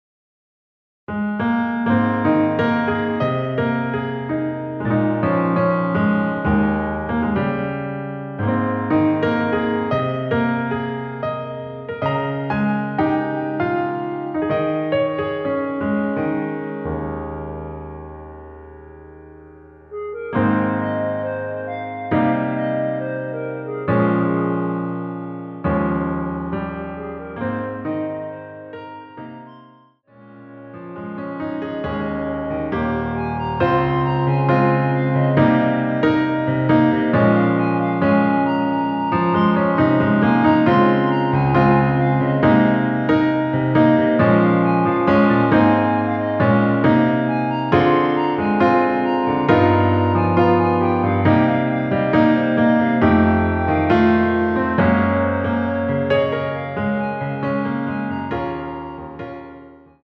원키에서(-1)내린 멜로디 포함된 MR입니다.
Ab
멜로디 MR이란
앞부분30초, 뒷부분30초씩 편집해서 올려 드리고 있습니다.
중간에 음이 끈어지고 다시 나오는 이유는